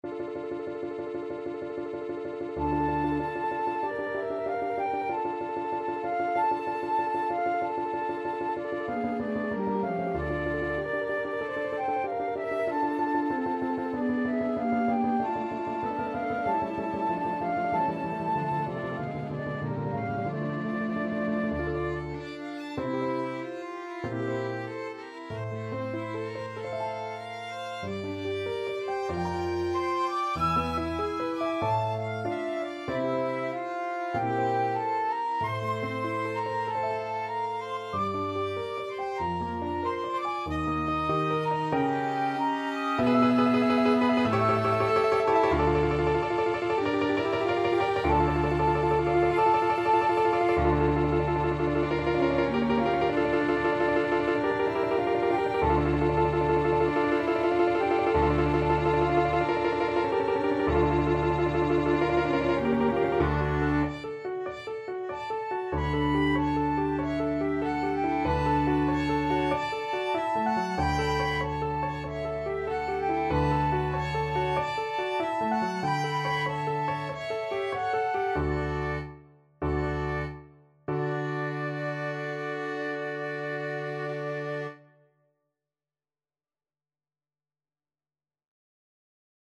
Flute
Violin
Clarinet
4/4 (View more 4/4 Music)
Andante = 95